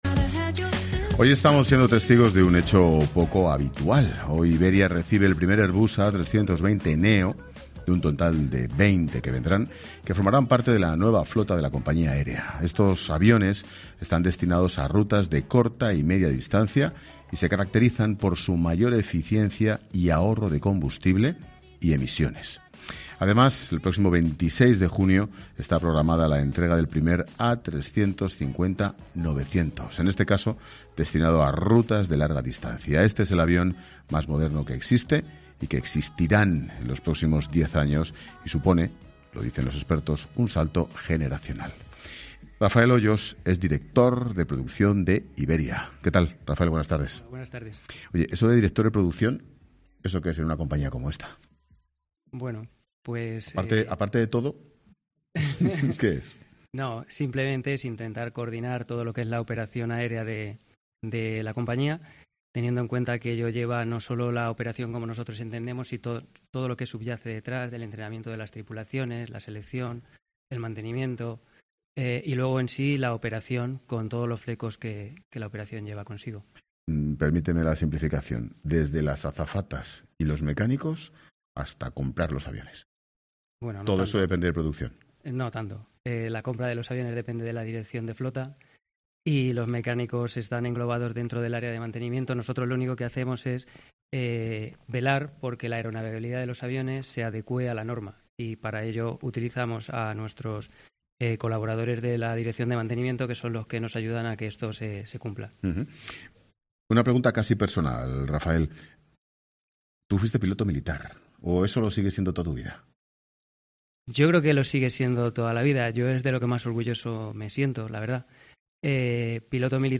Hablamos en 'La Tarde' con el Presidente ejecutivo de Iberia, Luis Gallego